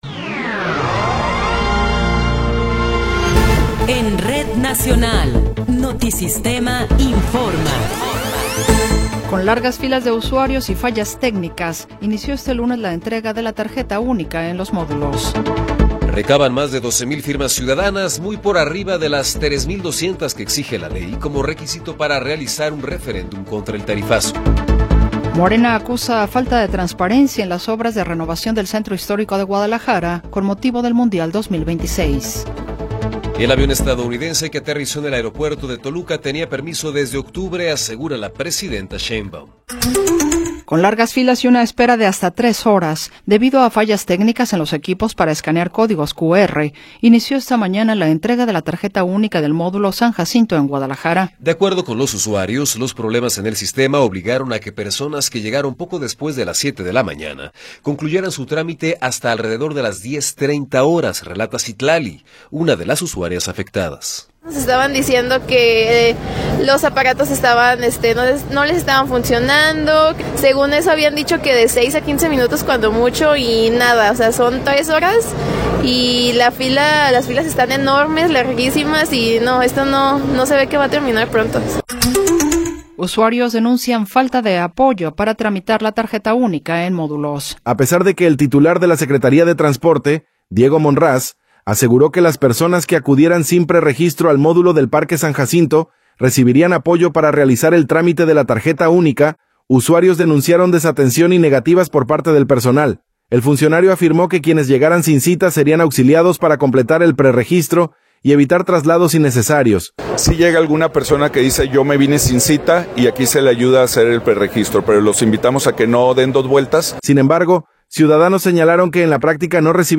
Noticiero 14 hrs. – 19 de Enero de 2026